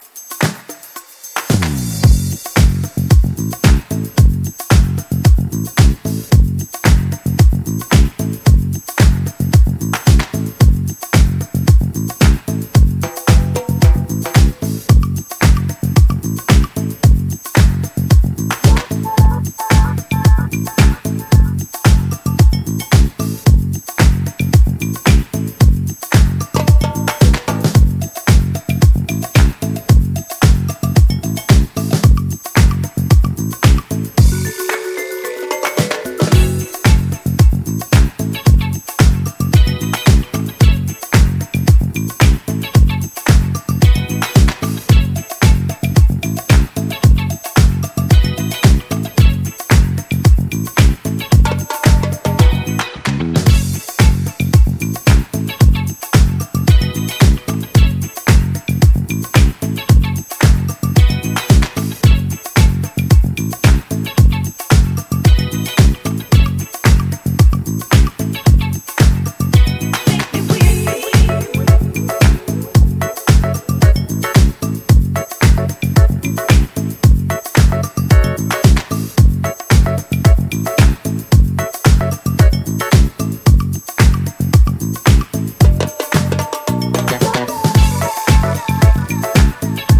ジャンル(スタイル) DISCO / NU DISCO